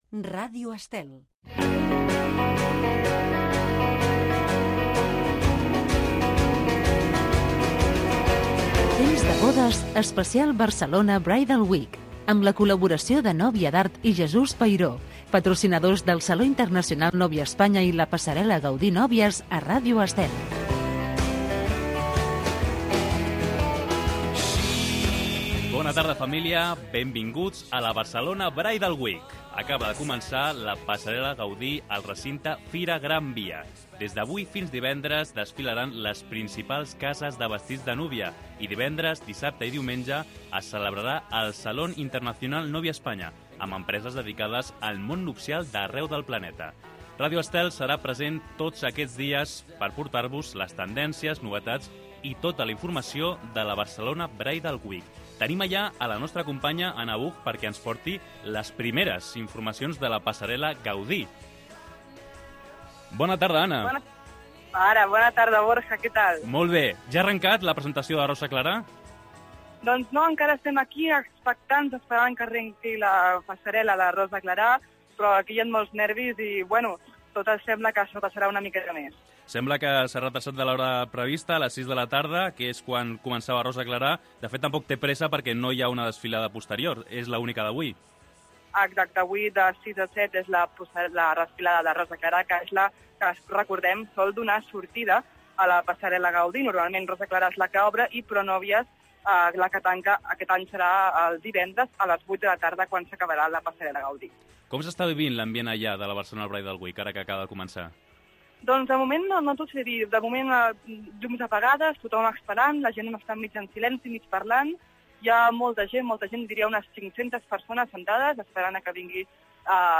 Indicatiu de l'emissora, careta del programa, espai dedicat a la Barcelona Bridal Week 2013, connexió amb Fira de Barcelona on ha de començar la desfilada de Rosa Clarà, indicatiu del programa
Divulgació